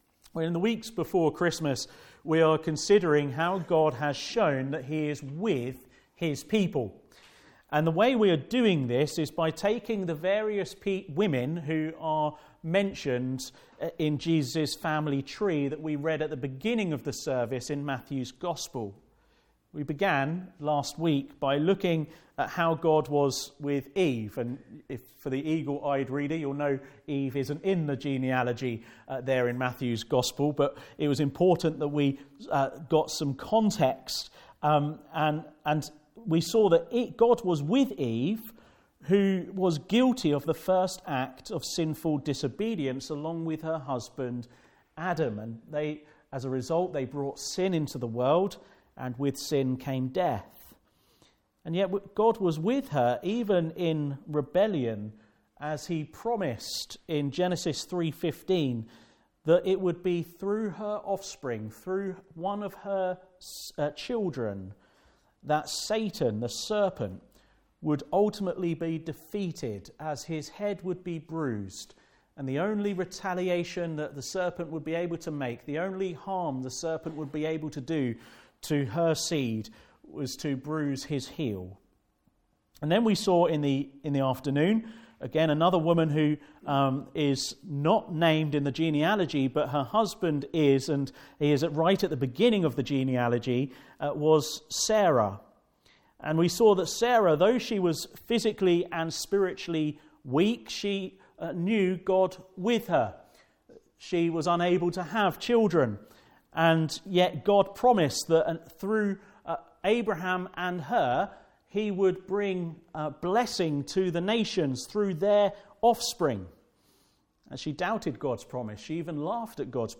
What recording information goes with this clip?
God with Us Passage: Genesis 38: 1 - 30 Service Type: Sunday Morning « God with the Weak